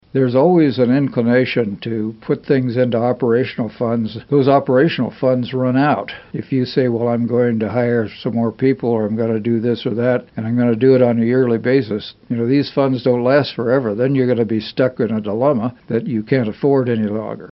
Dodson was a guest on KMAN’s In Focus Monday.